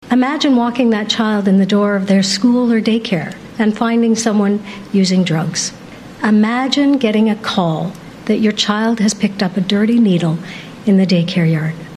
That was Health Minister Sylvia Jones, at a press conference following the bill being presented.